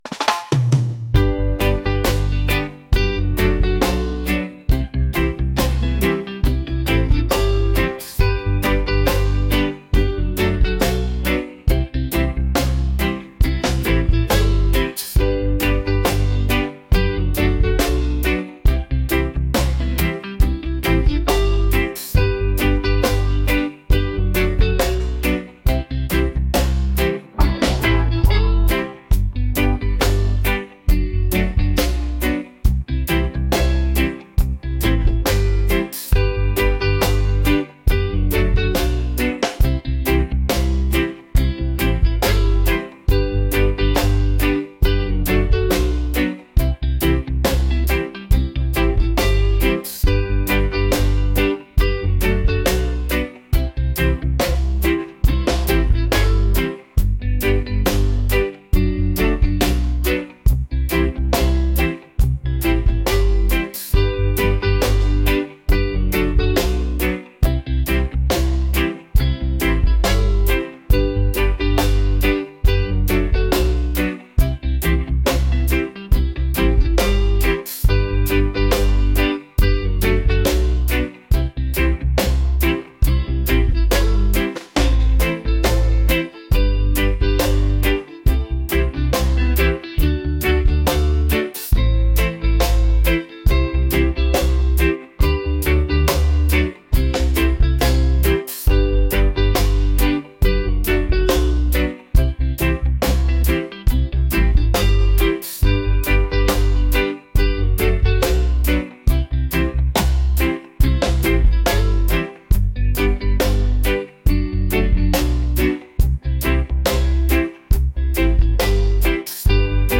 romantic | laid-back | reggae